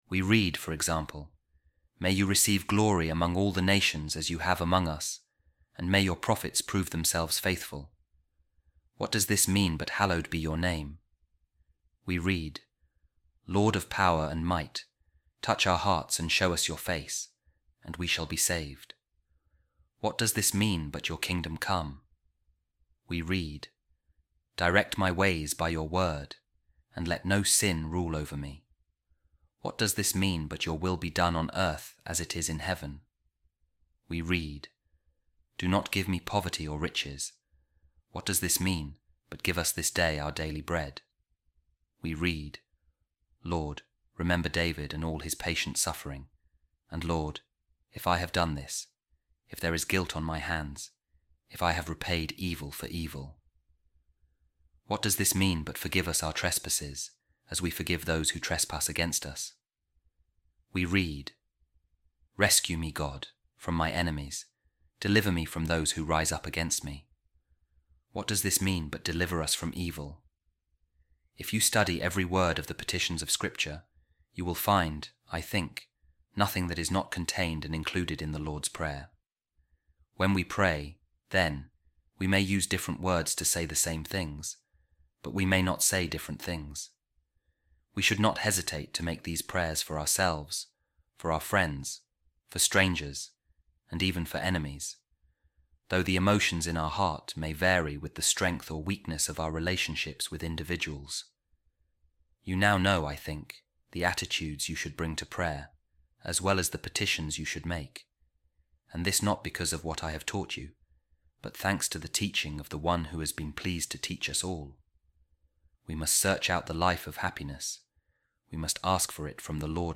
A Reading From The Letter Of Saint Augustine To Proba | You Will Find Everything In The Lord’s Prayer